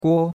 guo1.mp3